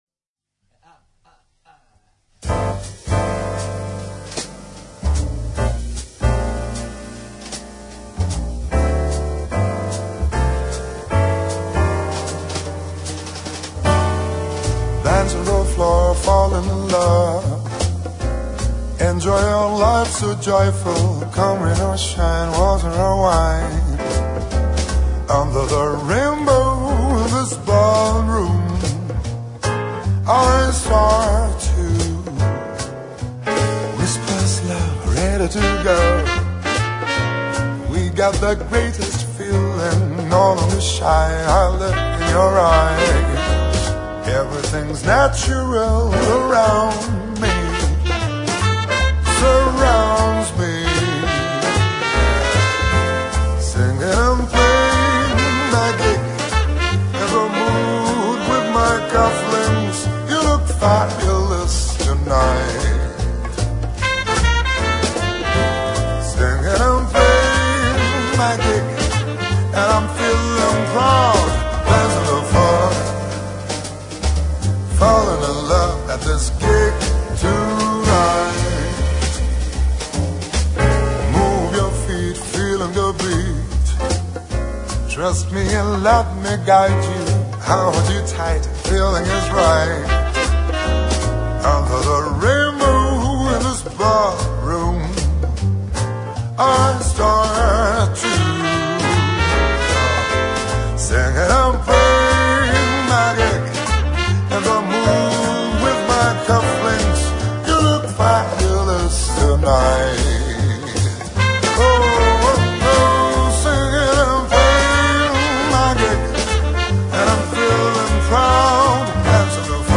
Jazz Blues Para Ouvir: Clik na Musica.